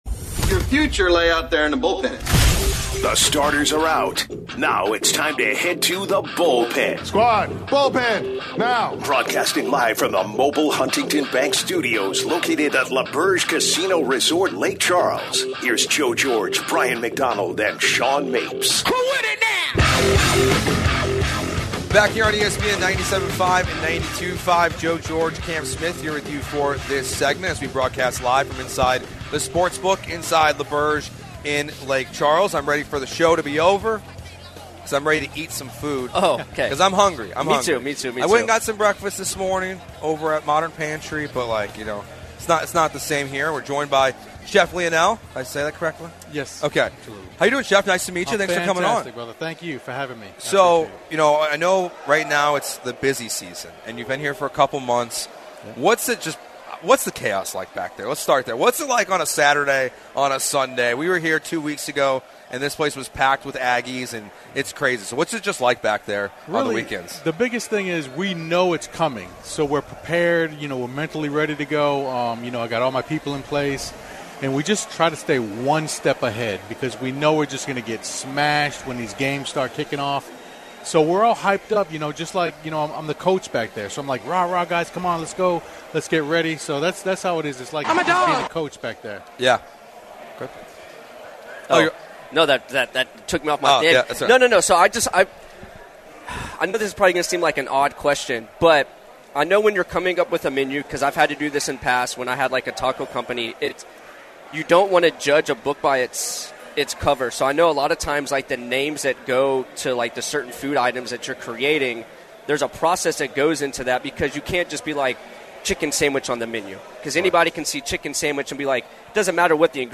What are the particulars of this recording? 11/08/25 Hour 2 (Live from L'auberge in Lake Charles)- New food Items + who you should be betting on today!